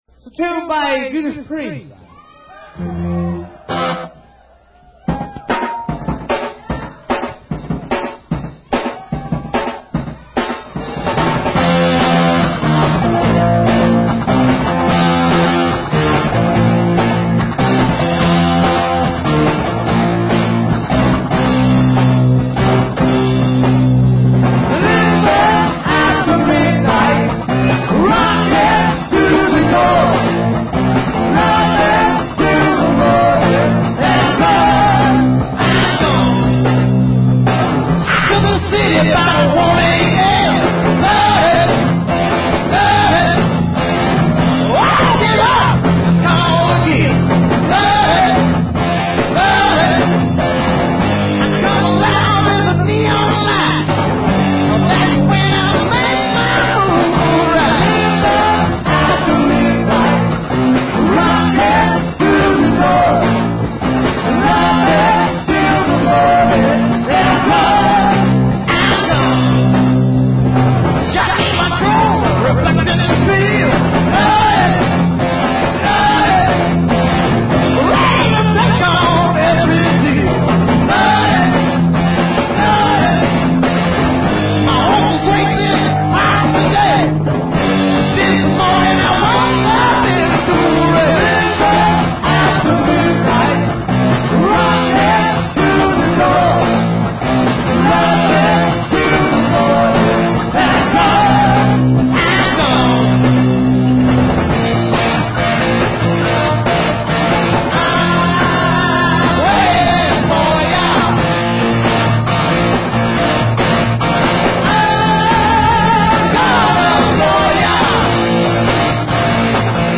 What a rare find for me. I dug up some old cassettes !